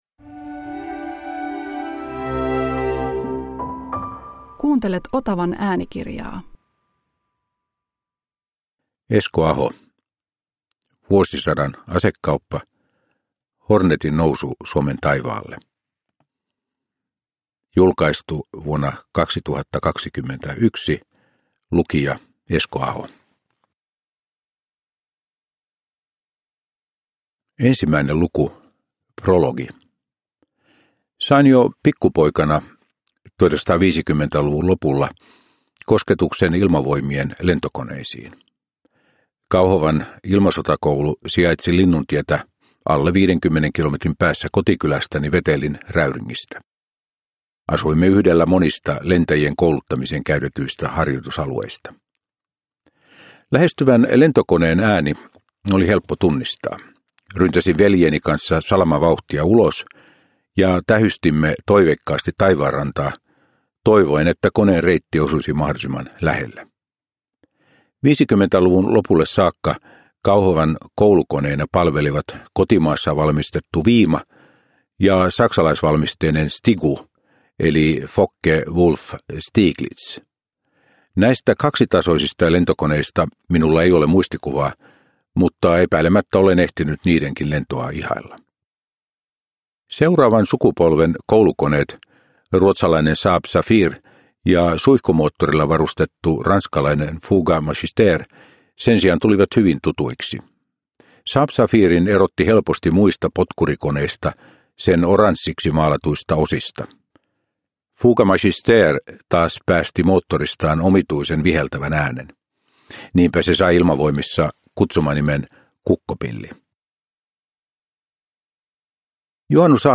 Vuosisadan asekauppa – Ljudbok – Laddas ner
Uppläsare: Esko Aho